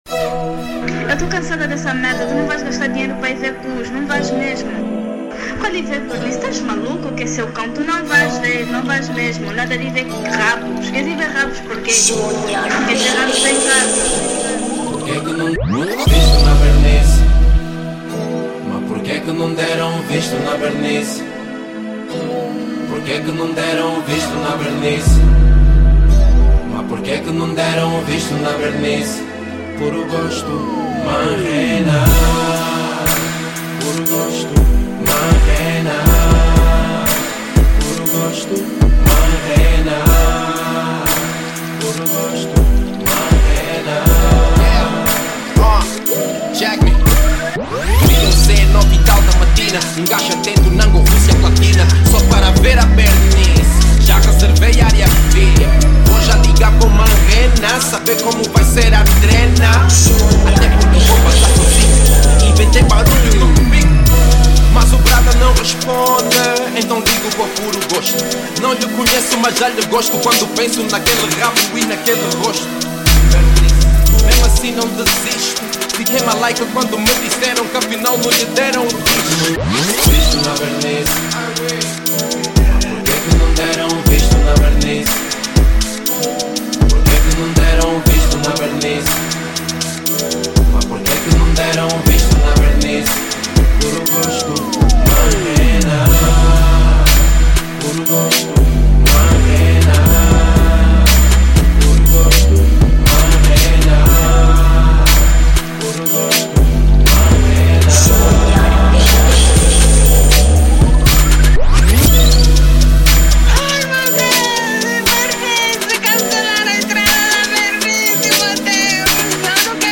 R&B|Sem intro